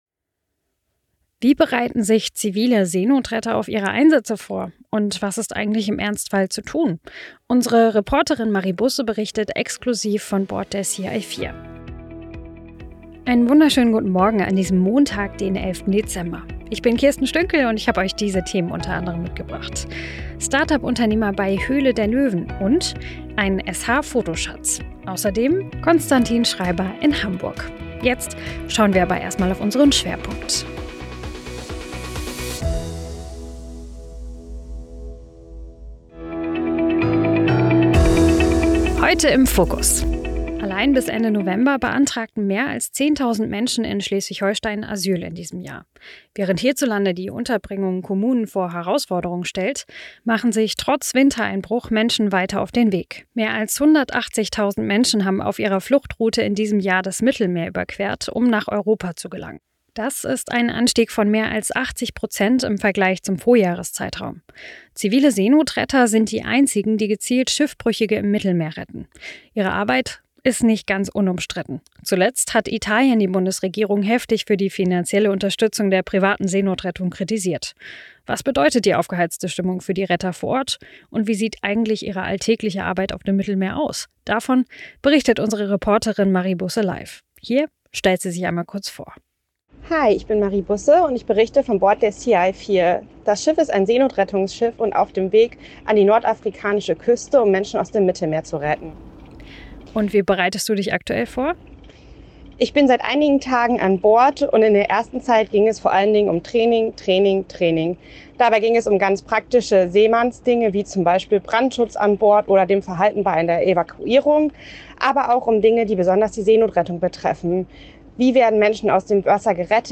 11.12. Seenotrettung im Mittelmeer: Reporterin berichtet von Bord der Sea-Eye 4
Nachrichten